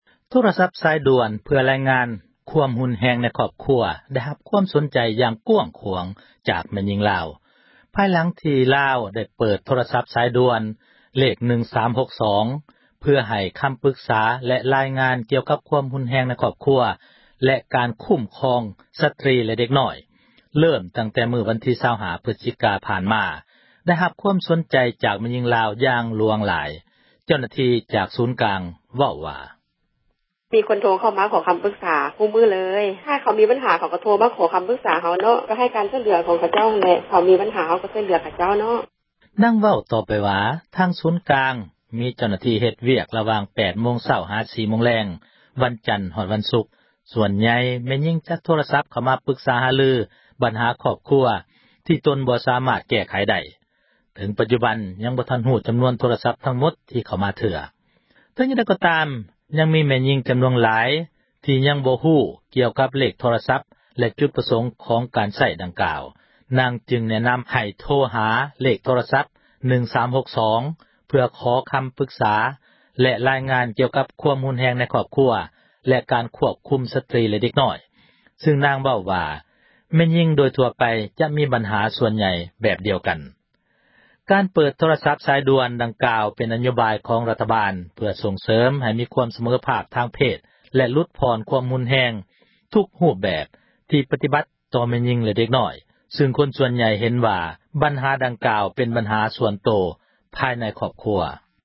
ເຈົ້າໜ້າທີ່ ຈາກສູນກາງ ສາຍສຸກເສີນ ເວົ້າວ່າ: